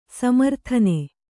♪ samarthane